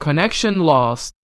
connection_lost.wav